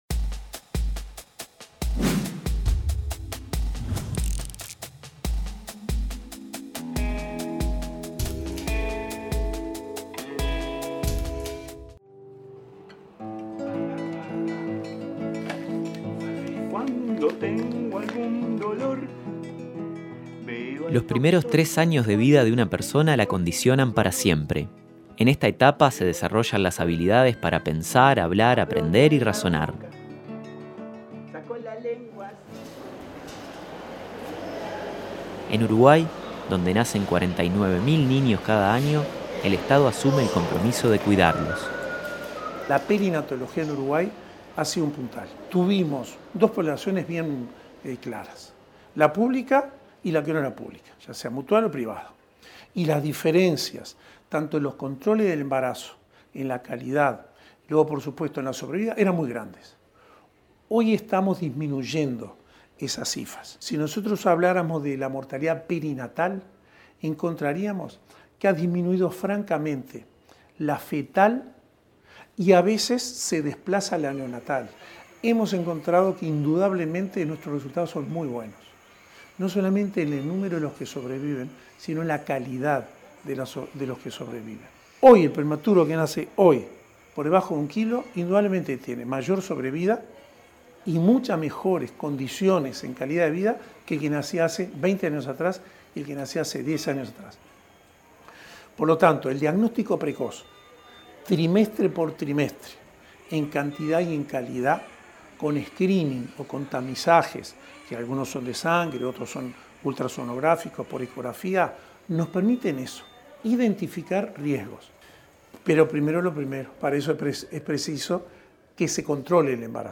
Gobierno difundió informe temático con énfasis en primera infancia 27/07/2017 Compartir Facebook X Copiar enlace WhatsApp LinkedIn El Gobierno emitió este jueves un informe temático por radio y televisión sobre primera infancia, con énfasis en controles del embarazo y del recién nacido, así como cuidados en los primeros tres años de vida. Esta transmisión es la continuación del ciclo dedicado a la difusión de las políticas destinadas a las nuevas generaciones de uruguayos.